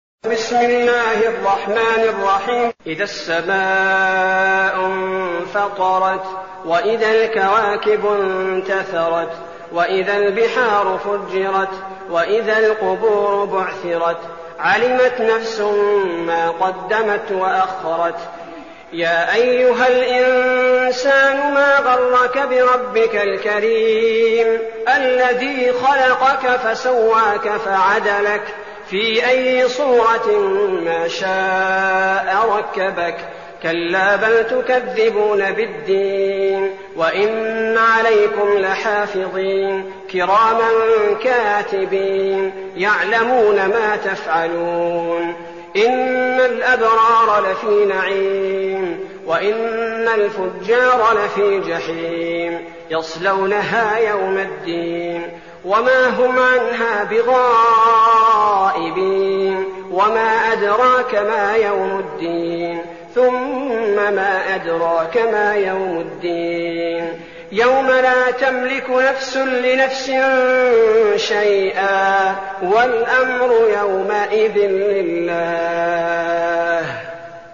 المكان: المسجد النبوي الشيخ: فضيلة الشيخ عبدالباري الثبيتي فضيلة الشيخ عبدالباري الثبيتي الانفطار